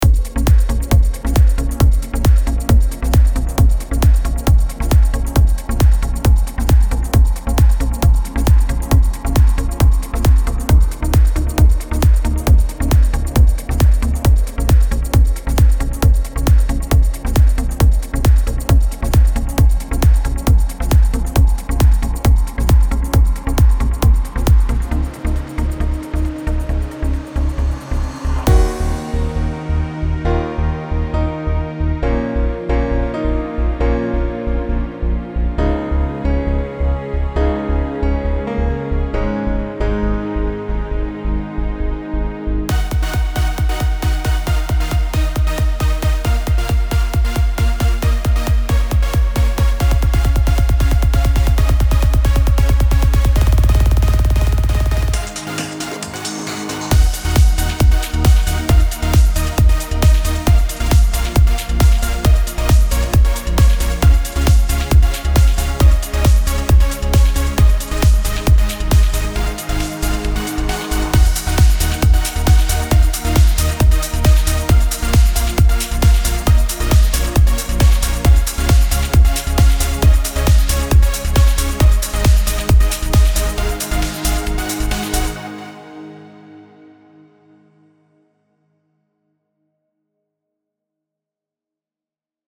Sample Packs